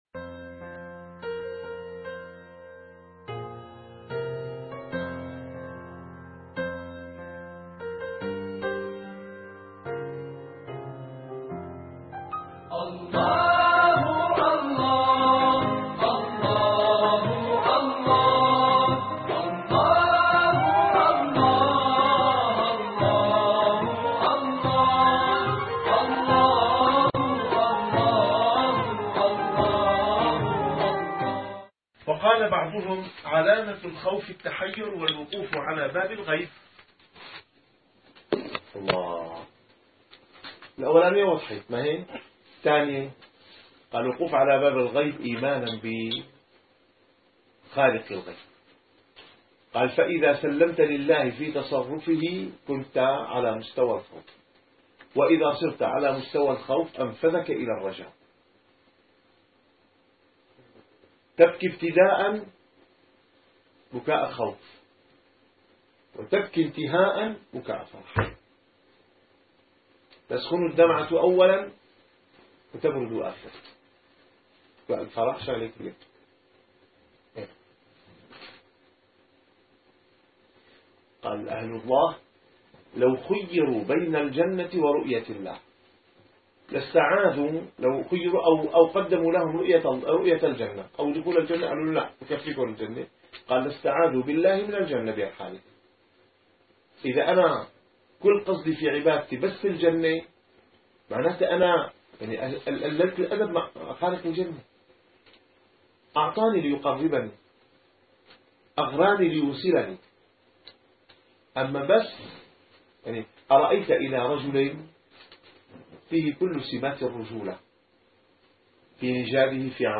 - الدروس العلمية - الرسالة القشيرية - الرسالة القشيرية / الدرس الثامن بعد المئة.